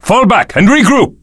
DraeneiMaleFlee03.wav